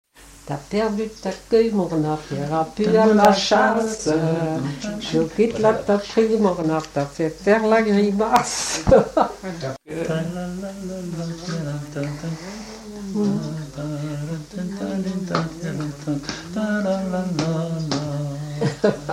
branle : courante, maraîchine
Pièce musicale inédite